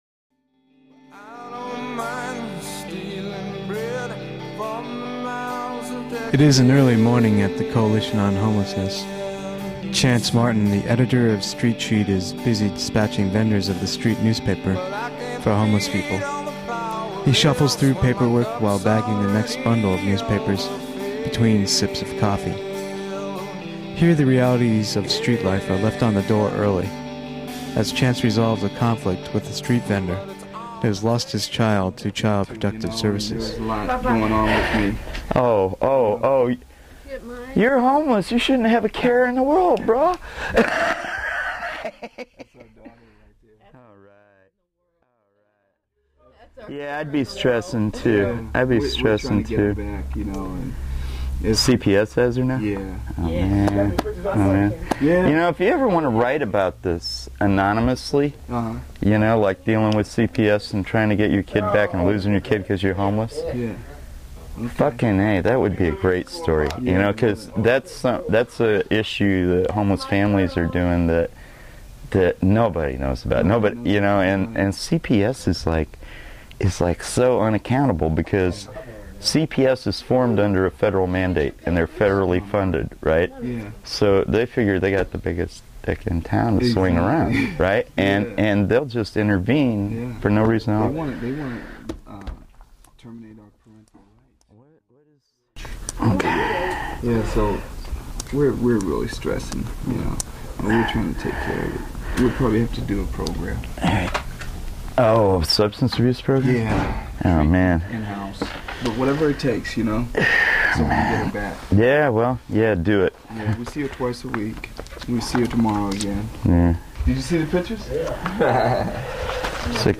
Audio documentary on poverty in SF | Gavin Newsom As "Punishing Parent" Of Homeless People | NYC's experience with "Care not Cash"